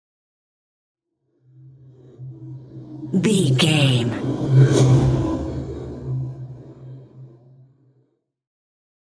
Scifi whoosh pass by deep
Sound Effects
futuristic
pass by
sci fi